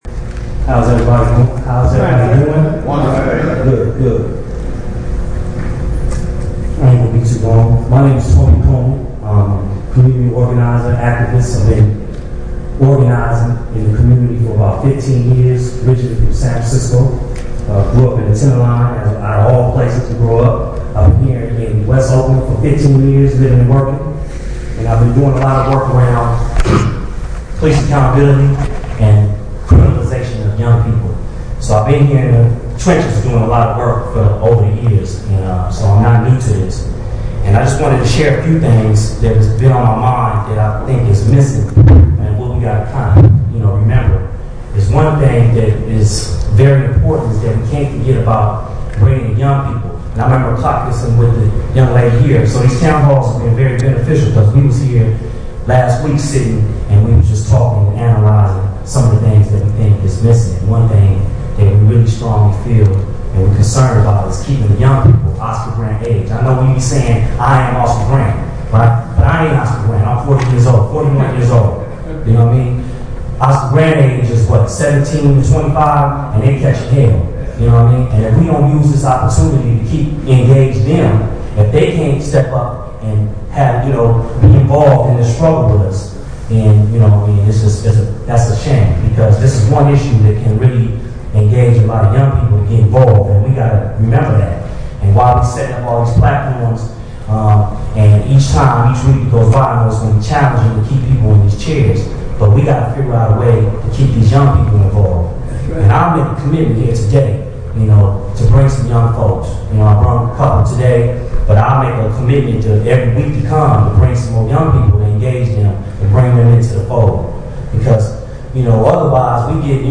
Town Hall Covers Mehserle Case and Recall of Orloff, Oakland, 3/21/09: photos and audio